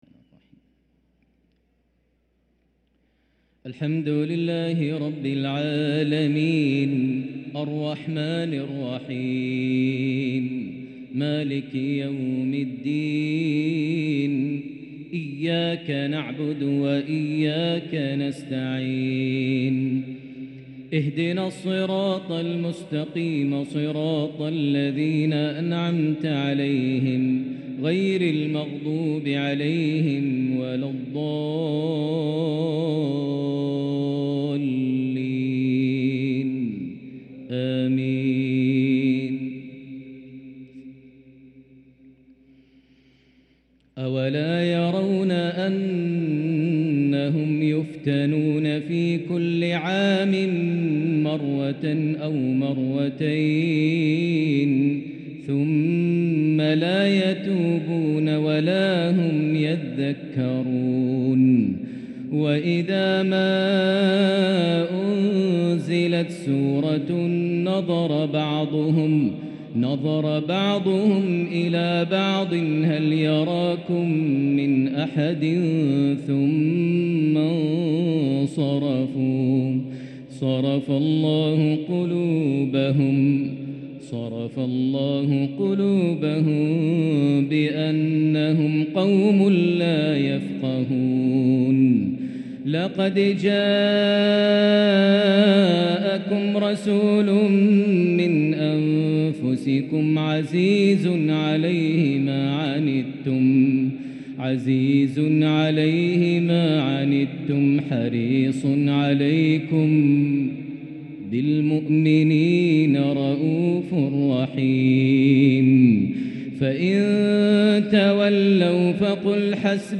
Maghrib Prayer from Surat al tawbah and Al-Fath 2-2-2023 > 1444 H > Prayers - Maher Almuaiqly Recitations